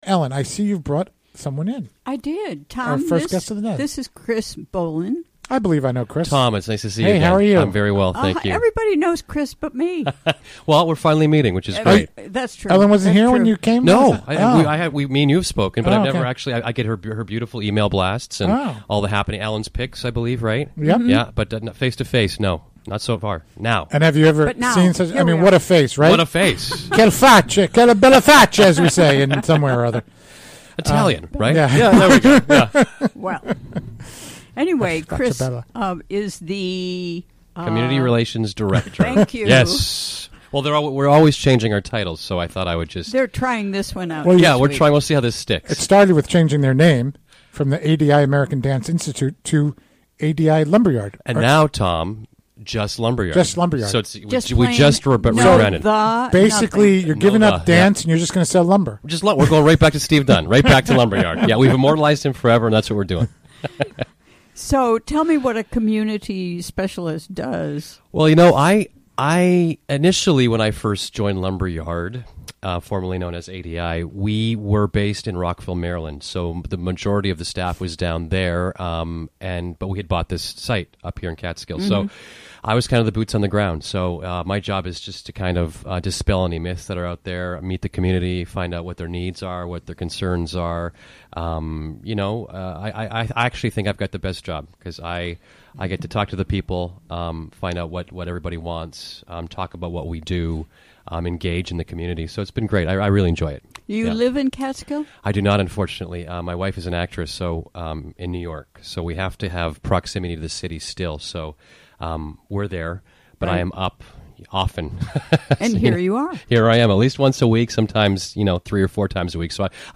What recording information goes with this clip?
Recorded during the WGXC Afternoon Show Thursday, February 16, 2017.